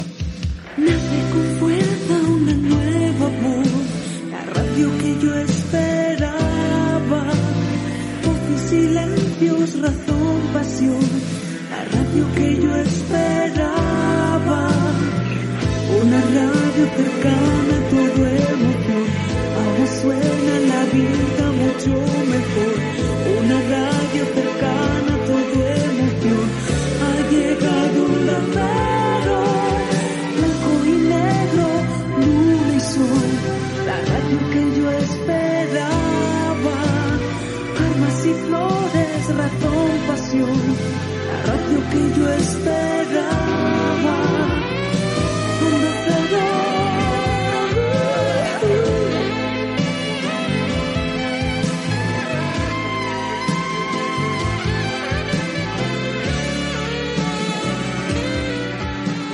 Cançó